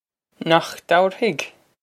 Nohk dow-er-hig?
This is an approximate phonetic pronunciation of the phrase.